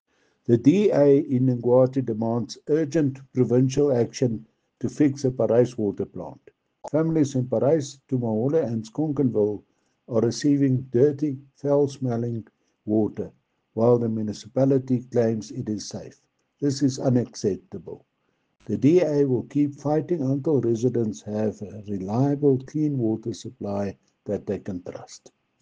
Afrikaans soundbites by Cllr Brendan Olivier and